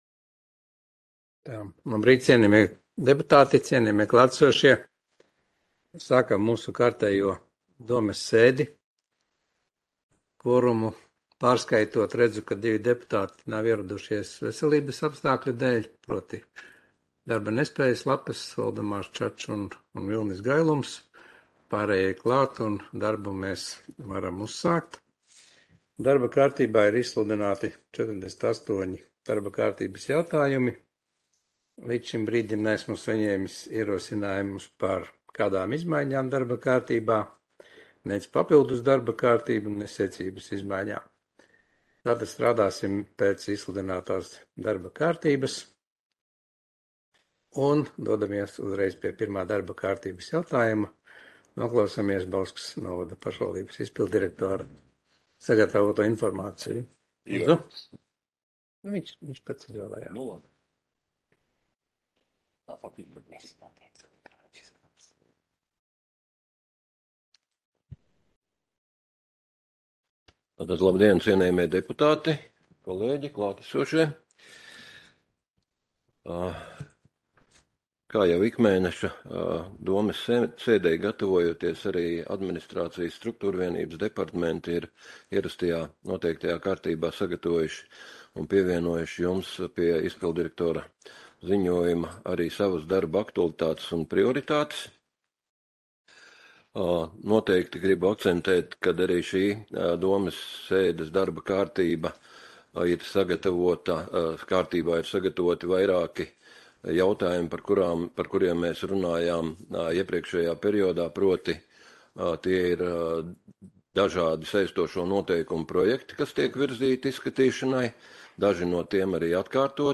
Audioieraksts - 2022.gada 25.augusta domes sēde